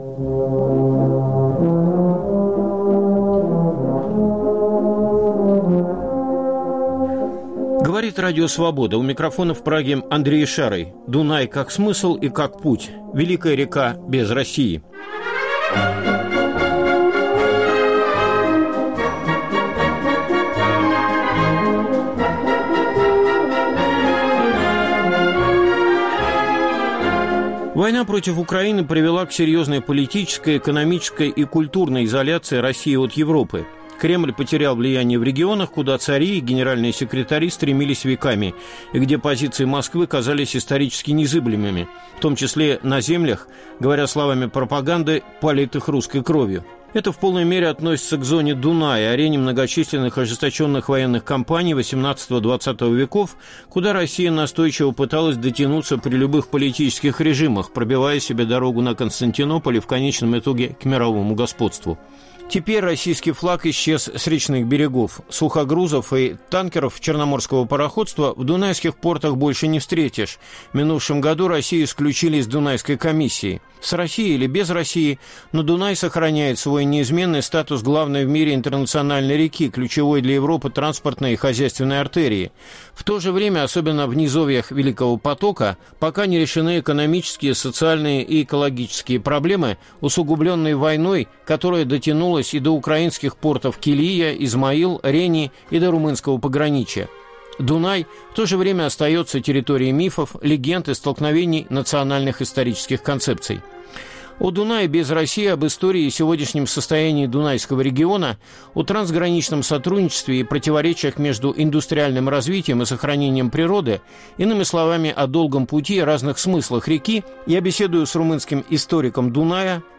Великая река без России. Беседа